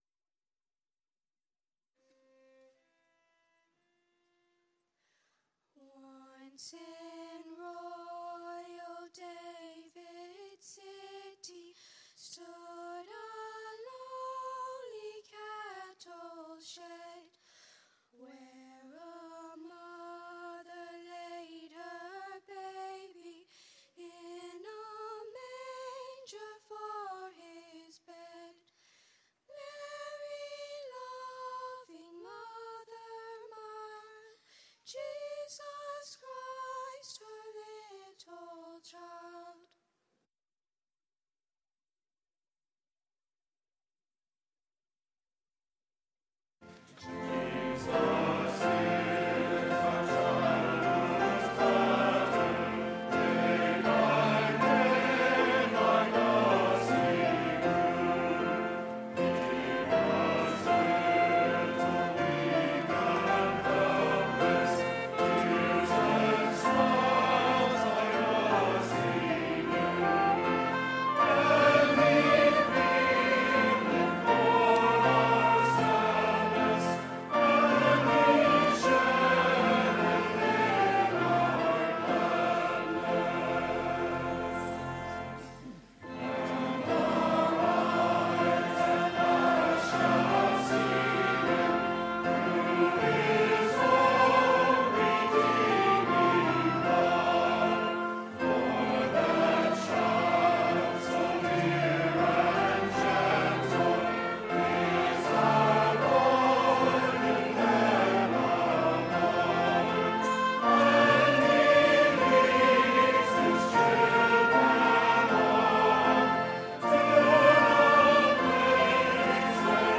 December 9, 2018 – Lessons & Carols Service
december-9-2018-lessons-carols-service.mp3